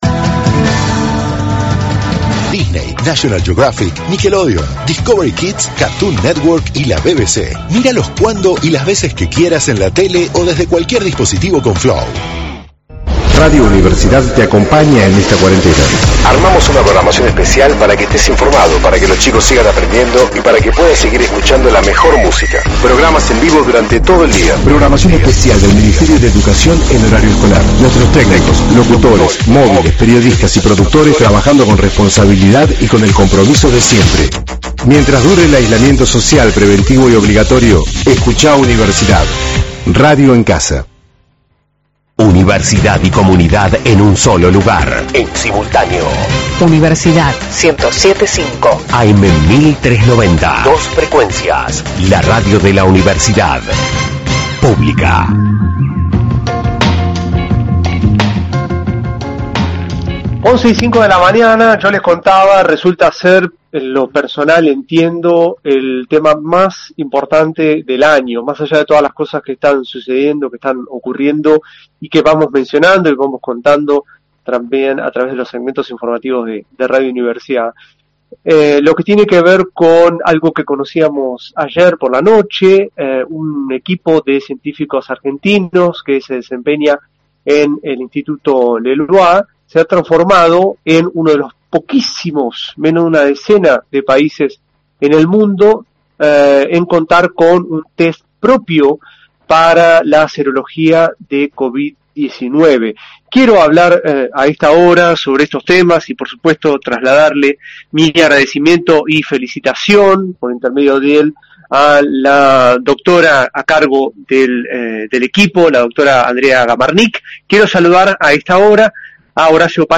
Argentina desarrolló un test serológico para Covid19: entrevista